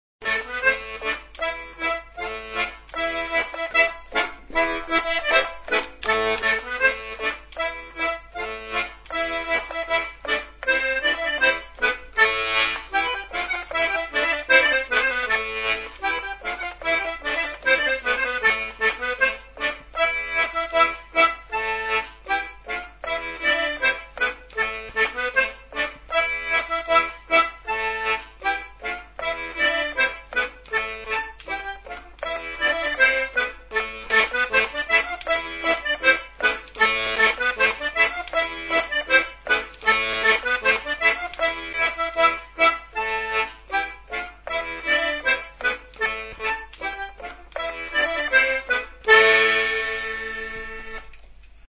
la nostra musica
Ascolta la "saltarella" eseguita con la "ddu botte"
saltarella.mp3